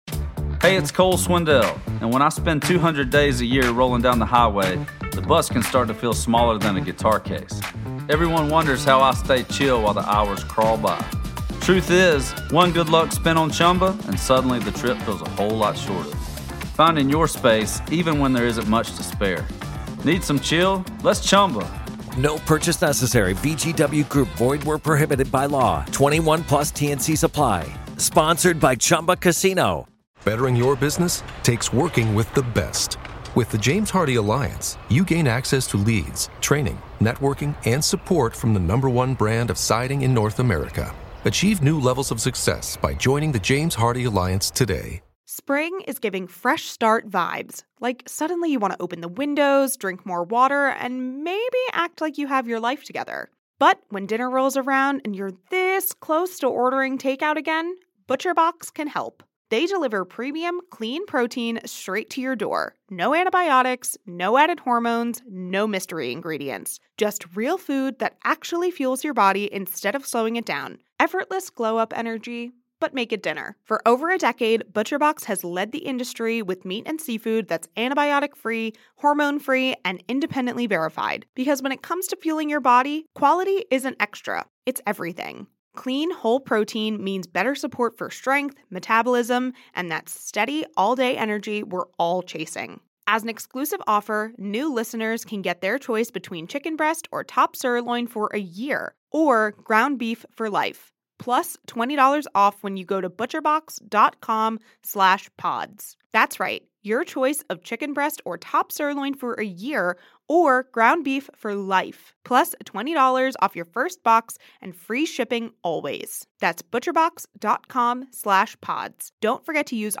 From shadow figures and strange sounds to the emotional weight that lingers in historic spaces, this conversation explores where preservation meets the paranormal—and how history and hauntings often occupy the same foundation.